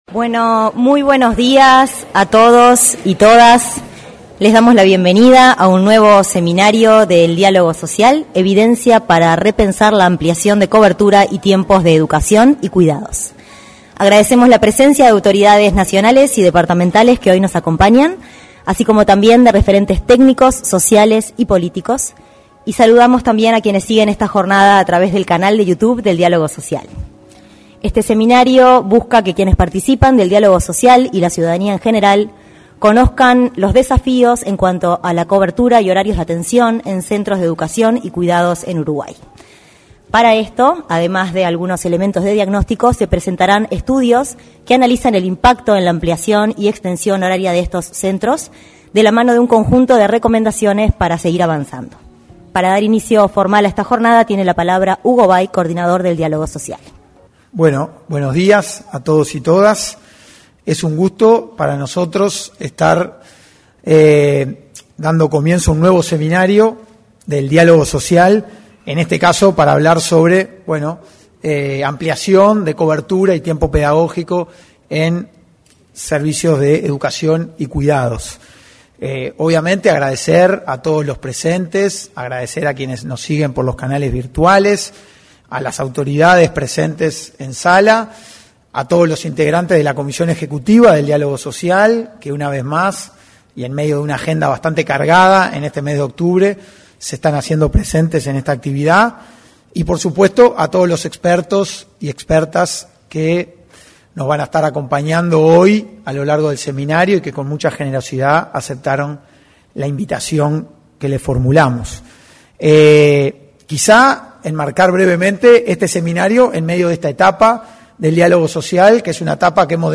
Seminario sobre Diálogo Social
Seminario sobre Diálogo Social 09/10/2025 Compartir Facebook Twitter Copiar enlace WhatsApp LinkedIn En el Seminario sobre Diálogo Social participaron, el coordinador del Diálogo Social, Hugo Bai; el vicepresidente del Instituto del Niño y Adolescente del Uruguay, Mauricio Fuentes; la Secretaria Nacional de Cuidados y Discapacidad del Ministerio de Desarrollo Social, Susana Muñiz, y el director nacional de Educación del Ministerio de Educación y Cultura, Gabriel Quirici.